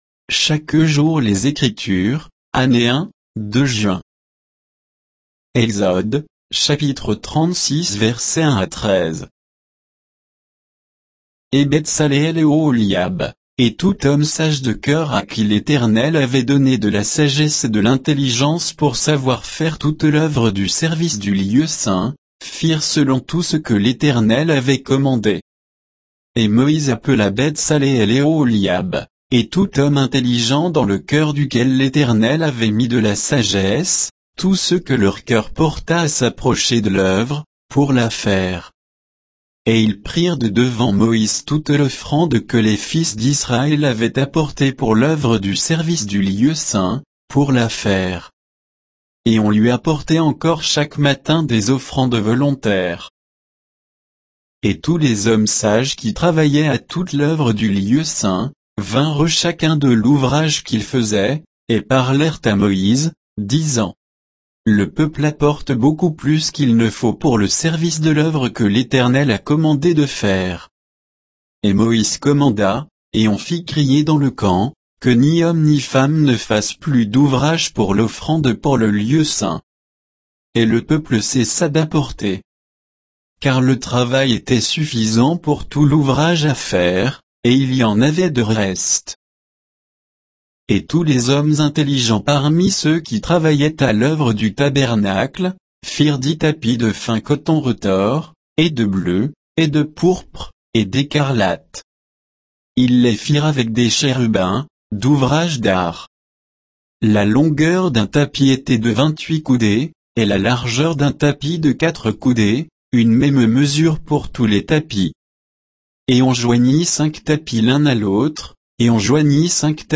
Méditation quoditienne de Chaque jour les Écritures sur Exode 36, 1 à 13